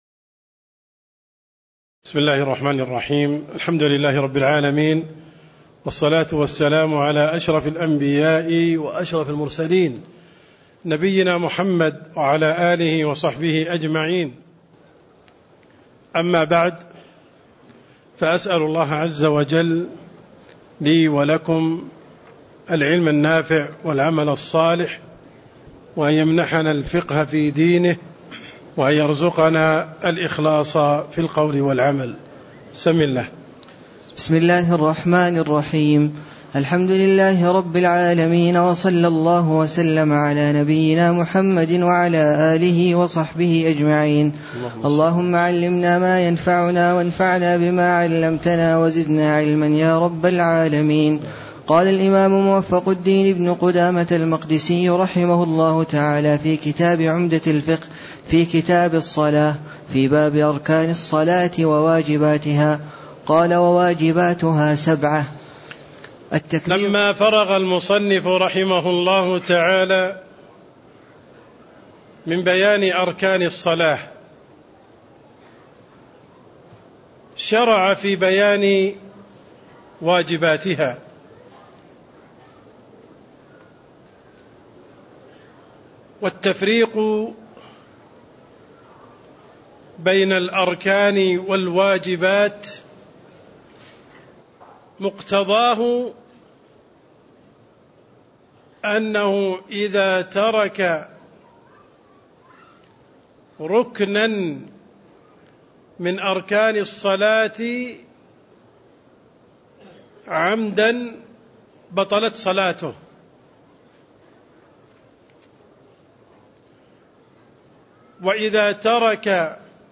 تاريخ النشر ٨ ربيع الأول ١٤٣٦ هـ المكان: المسجد النبوي الشيخ: عبدالرحمن السند عبدالرحمن السند باب أركان الصلاة وواجباتها (10)